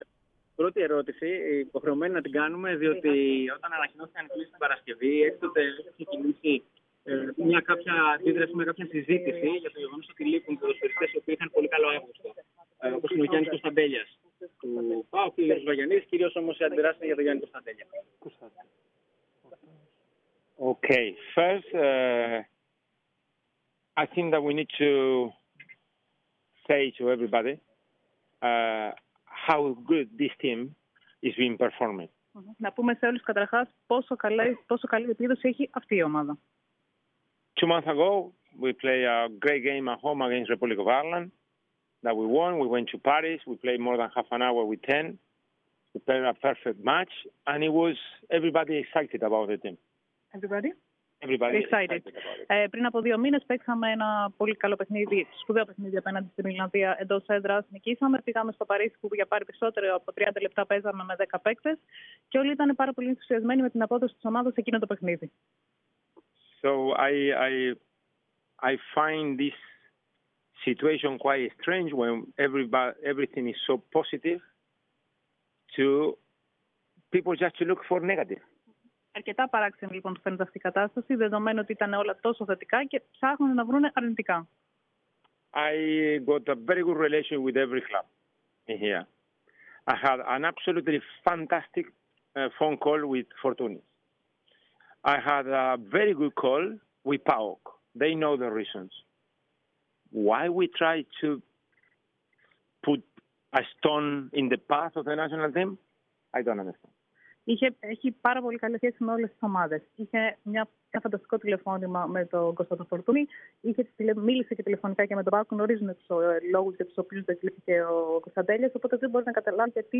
Ο Ομοσπονδιακός τεχνικός με δηλώσεις του κατά τη διάρκεια της προπόνηση της Εθνικής ομάδας στο προπονητικό κέντρο του Ατρομήτου αναφέρθηκε στα προσεχή ματς κόντρα σε Ολλανδία και Γιβραλτάρ αλλά και στις κλήσεις του.
Ακούστε παρακάτω όλα όσα δήλωσε ο Ομοσπονδιακός τεχνικός: